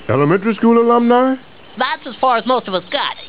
-Chef talking to Jimbo. 26kb